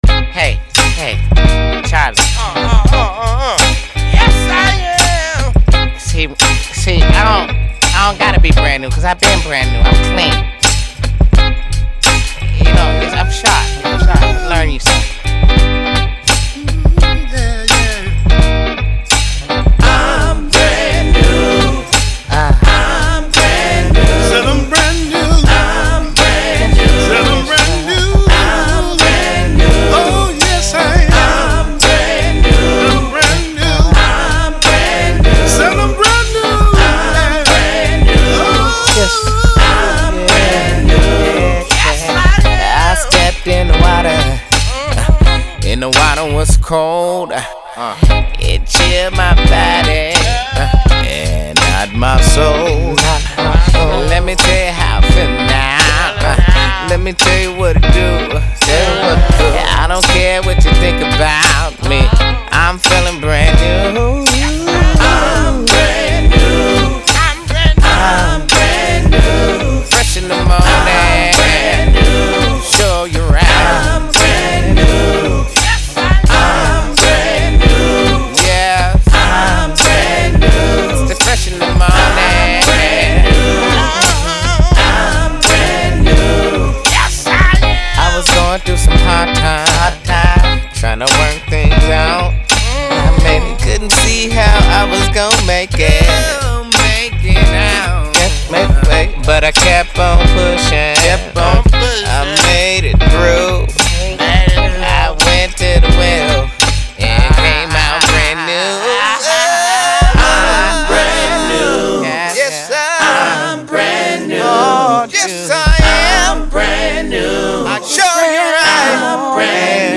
• Жанр: Soul